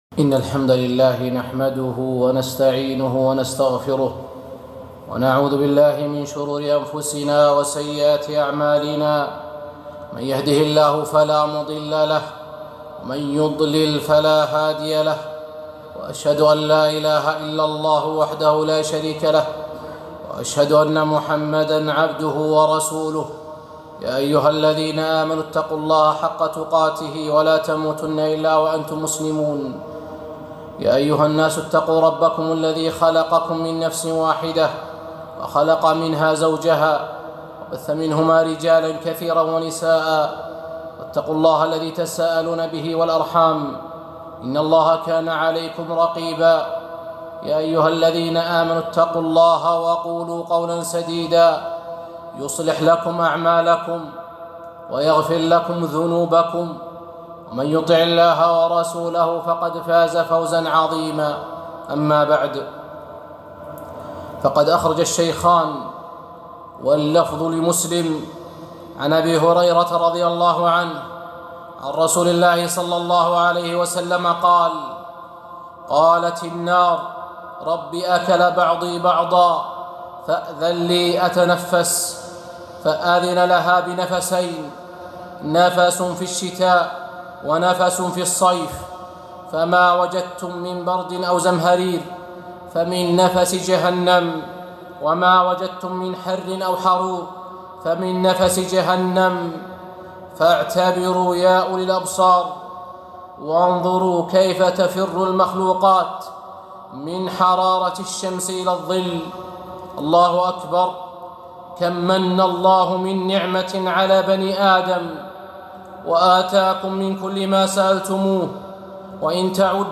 خطبة - حر الصيف